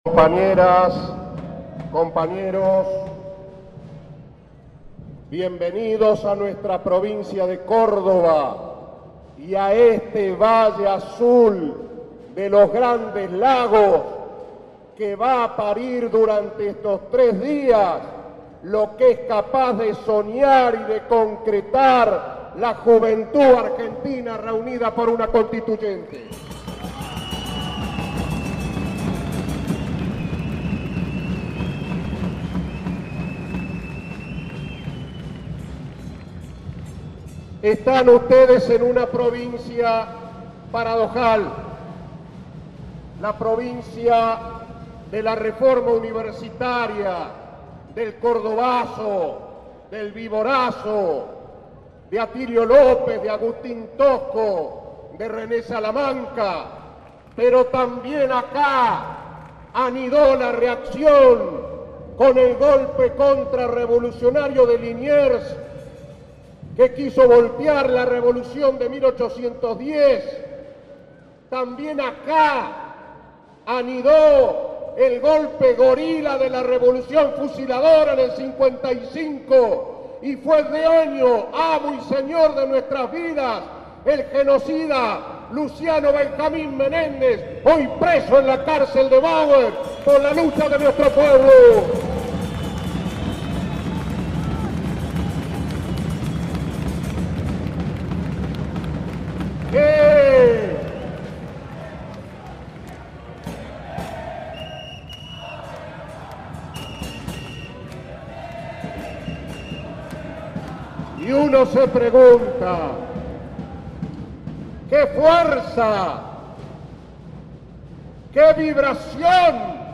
Discurso Apertura 1